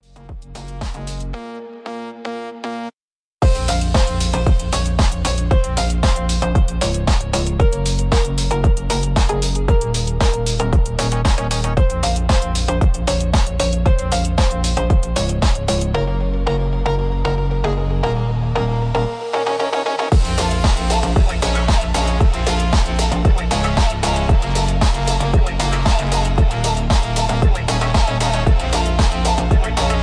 Instrumental, background.